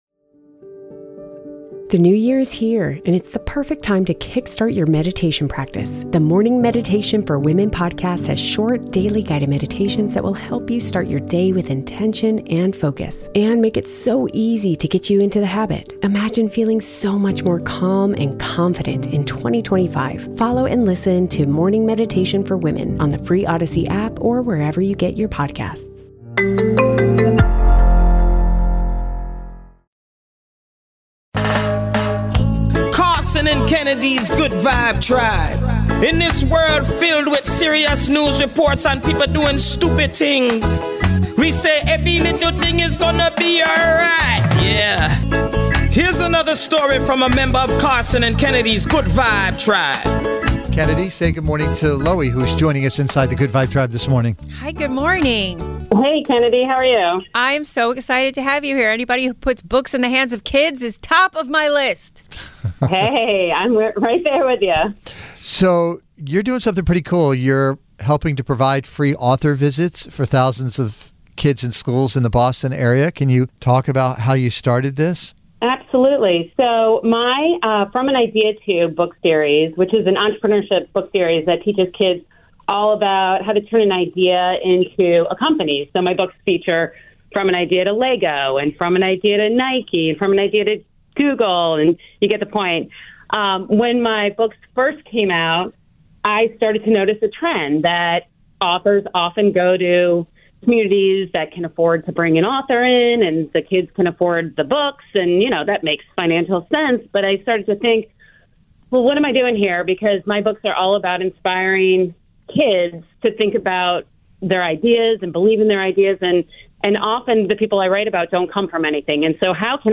The show is fast paced and will have you laughing until it hurts one minute and then wiping tears away from your eyes the next.